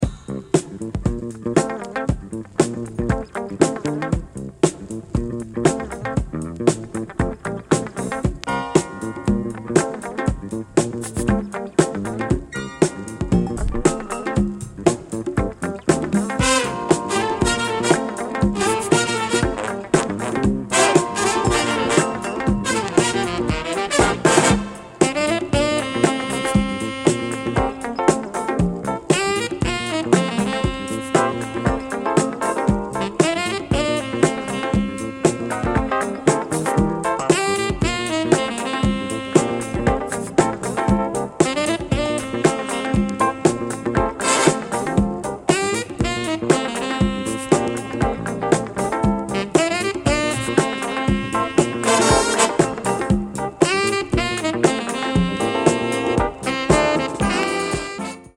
A very warm Jazzy-Funk 1980 release